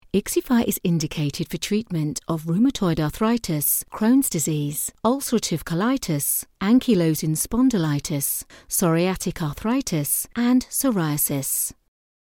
Medizinische Erzählung
Meine Stimme wird als nahbar, voll, selbstsicher, sanft, selbstbewusst und vertrauenswürdig beschrieben.
Professionelle Gesangskabine mit kabelloser Tastatur und Maus.
Focsurite Scarlett 2i2, D2 Synco-Richtmikrofon und Twisted-Wave-Aufnahmesoftware.
Niedrig